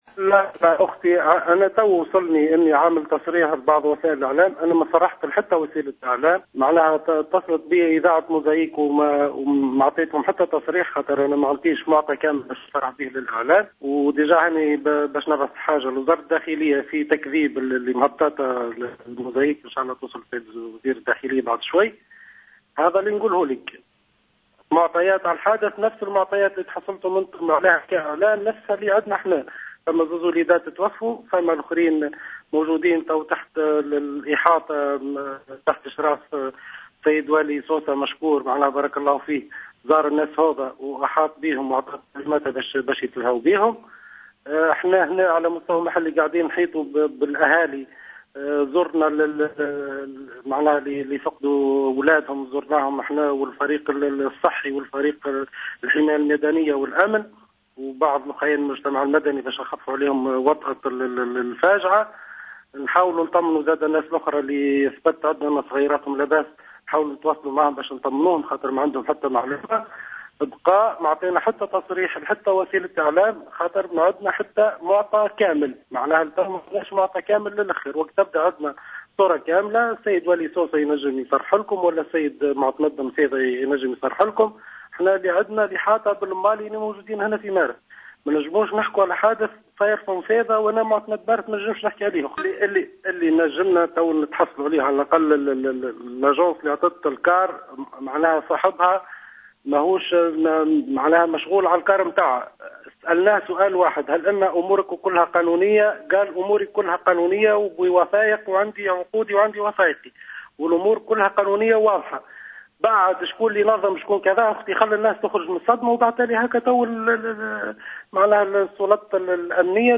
وقال المعتمد في تصريح للجوهرة أف أم، إنه علم بهذه التصريحات المنسوبة إليه من بعض وسائل الإعلام، وفند تصريحه بأية تفاصيل تخص الحادث الذي أودى بحياة طفلين، مشيرا إلى أن والي سوسة ومعتمد النفيضة هما المخولان لتقديم إفاداتهما بخصوص الحادث.